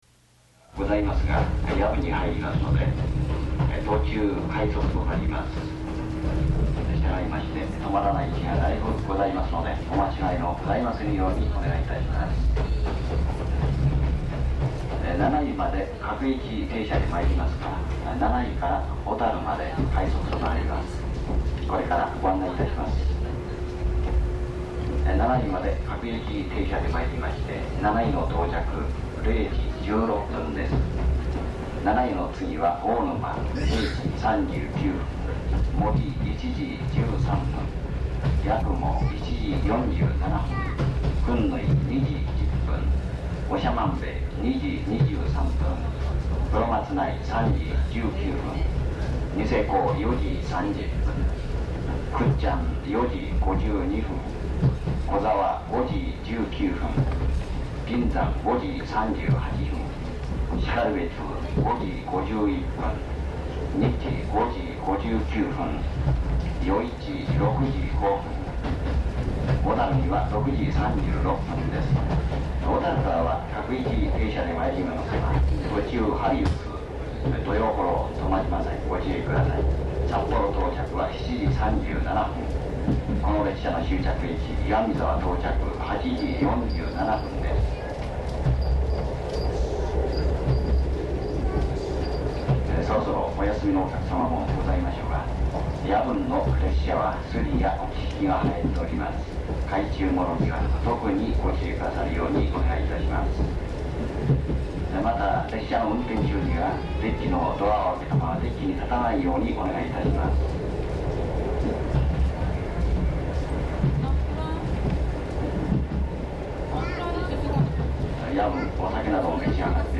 この時は録音用に90分のカセットテープを1本持って行っただけだったので、テープをけちって車内放送が始まる頃合いを見計らって
ギリギリ待っているといきなり始まったので、頭の部分が録れませんでした。
しかし旧客の夜行鈍行列車らしいユニークな車内放送です。最後の方もなぜかちょん切れています。